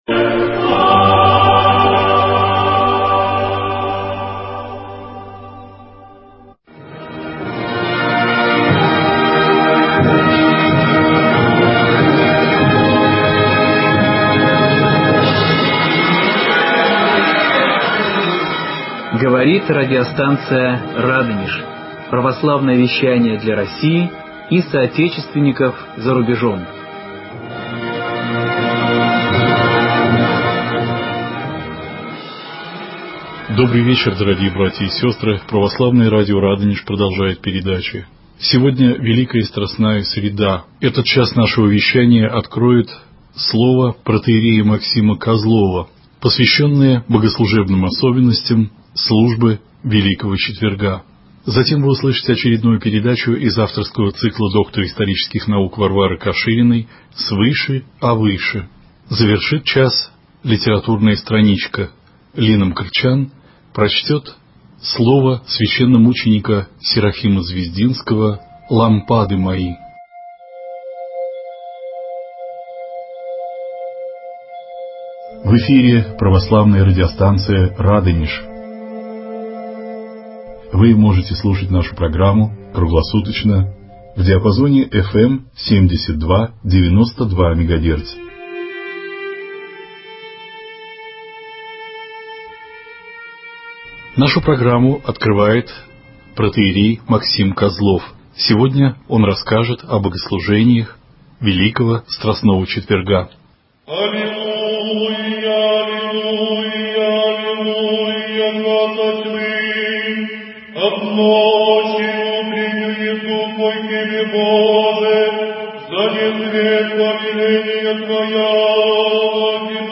В эфире радио «Радонеж»